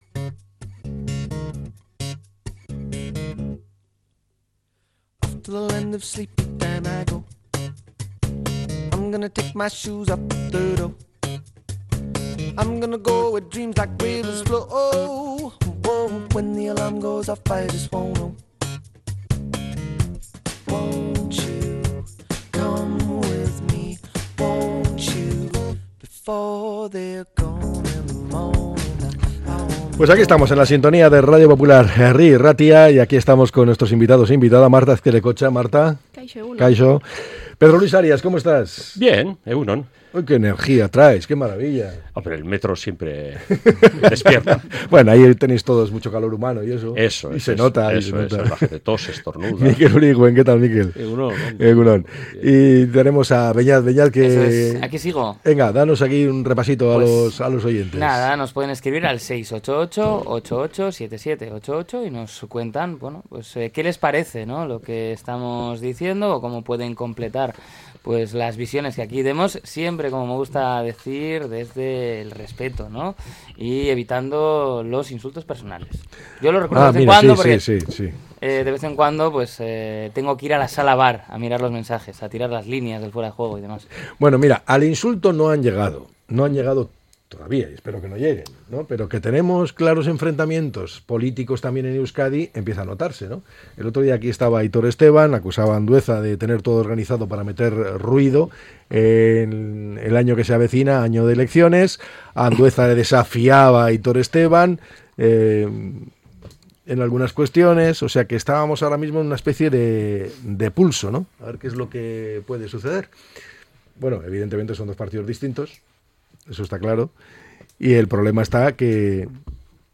La Tertulia 16-02-26.